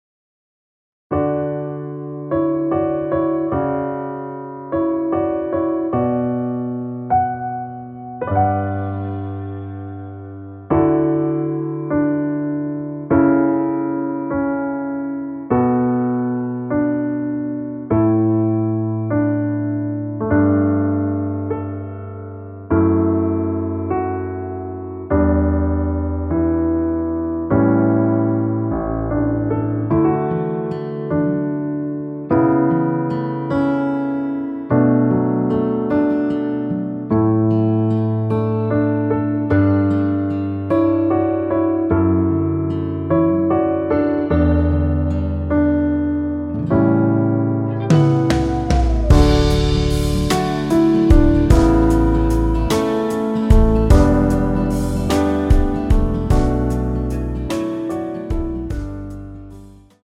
원키에서(-3)내린 MR입니다.
D
앞부분30초, 뒷부분30초씩 편집해서 올려 드리고 있습니다.
중간에 음이 끈어지고 다시 나오는 이유는